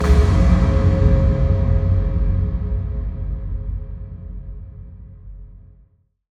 Impact 25.wav